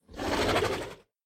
Minecraft Version Minecraft Version snapshot Latest Release | Latest Snapshot snapshot / assets / minecraft / sounds / mob / horse / zombie / idle3.ogg Compare With Compare With Latest Release | Latest Snapshot